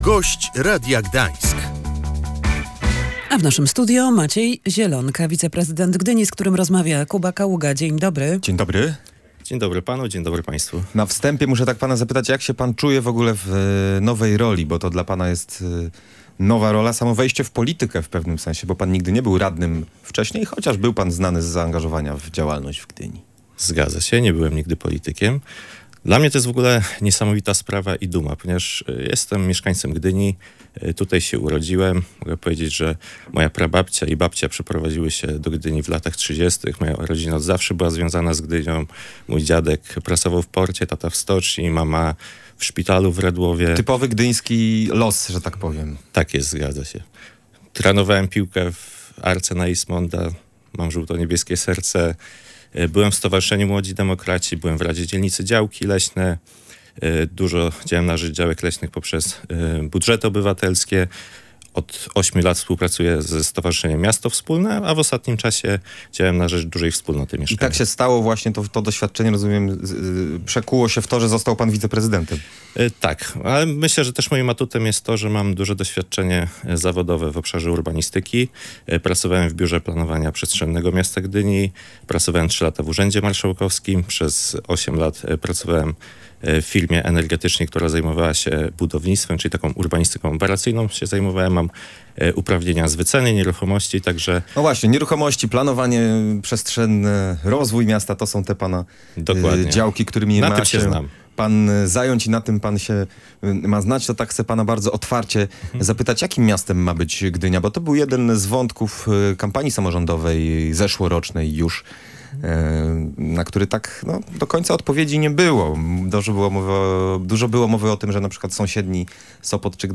Gdynia się wyludnia. Mieszkańcy przeprowadzają się do sąsiednich gmin. Jednym ze sposobów na ich zatrzymanie może być nowe budownictwo komunalne – mówił w Radiu Gdańsk wiceprezydent Maciej Zielonka.